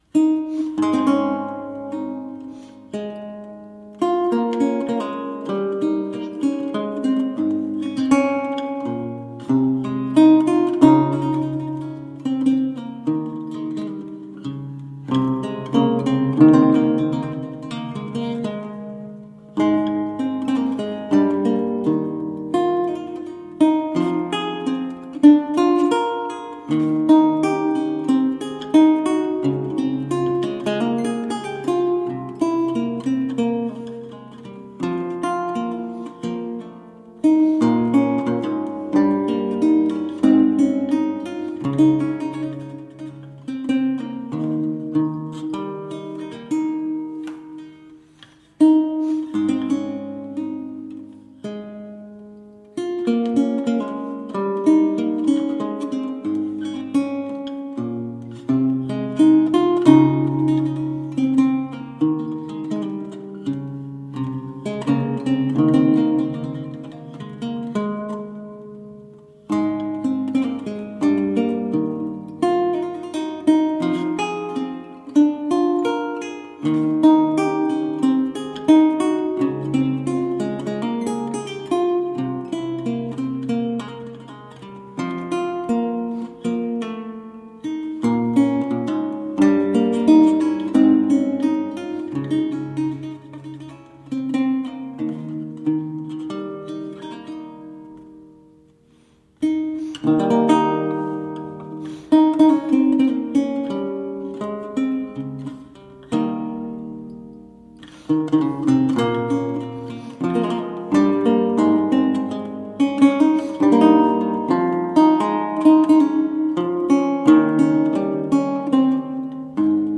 Vihuela, renaissance and baroque lute
Classical, Baroque, Renaissance, Instrumental
Lute